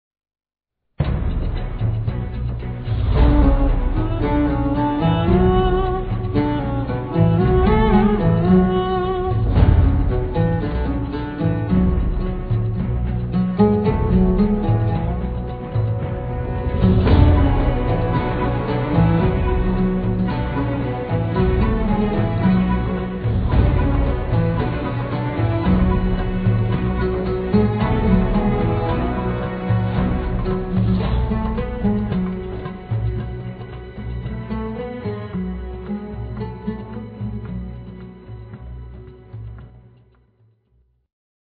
این قطعه فضای حماسی فیلم را تداعی می کند